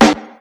• Old School Hot Steel Snare Drum D Key 10.wav
Royality free steel snare drum tuned to the D note. Loudest frequency: 1148Hz
old-school-hot-steel-snare-drum-d-key-10-bPZ.wav